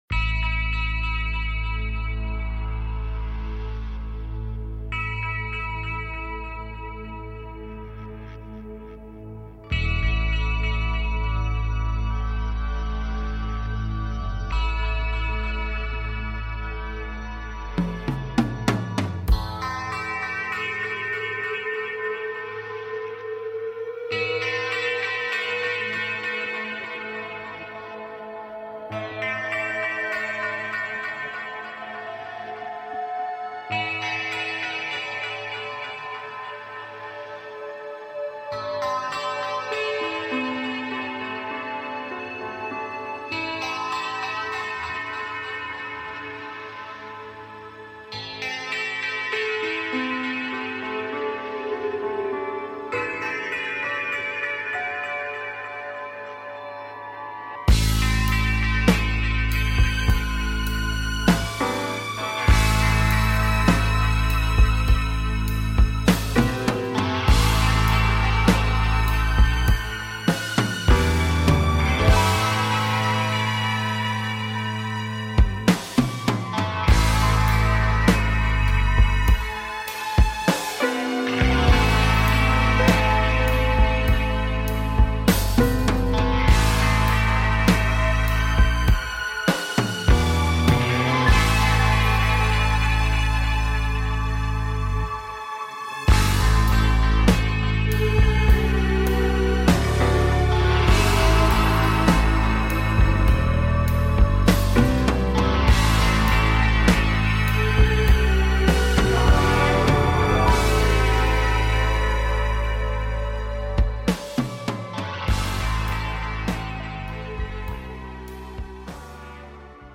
Show on Homesteading and taking caller questions